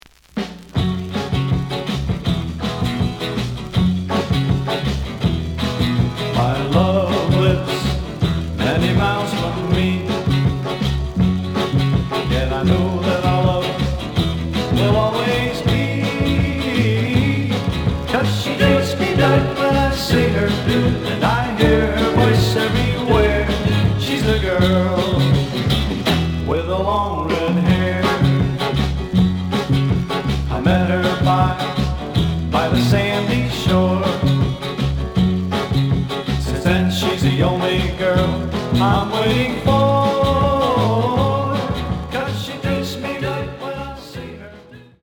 The audio sample is recorded from the actual item.
●Genre: Rock / Pop
Slight noise on A side.